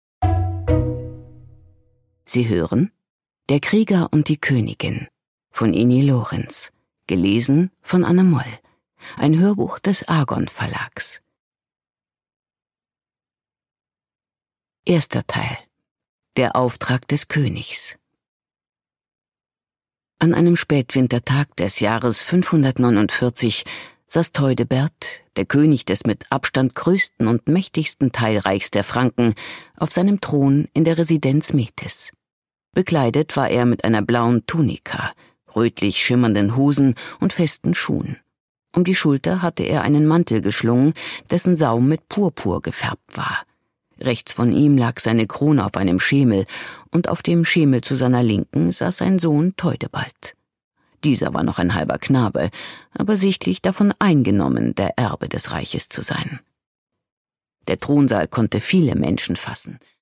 Produkttyp: Hörbuch-Download
gewohnt mitreißend und mit warmer, melodiöse Stimme.